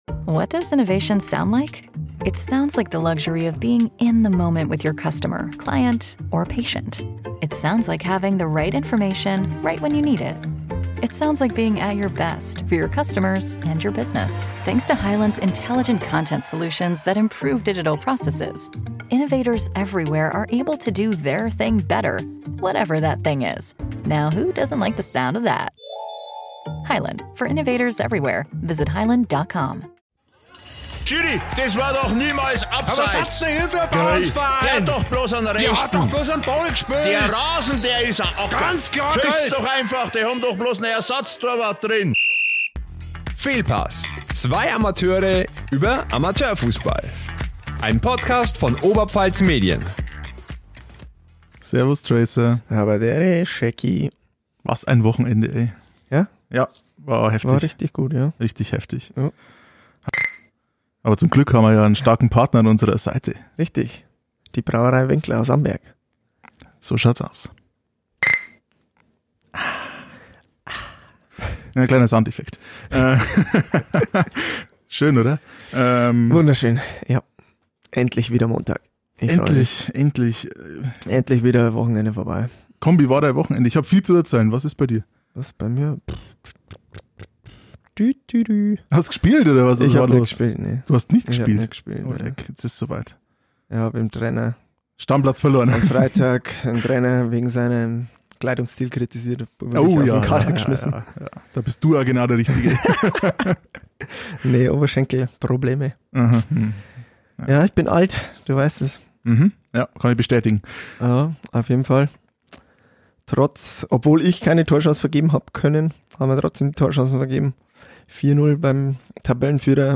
Zudem haben wir eine Sprachnachricht vom ersten Meister der Saison.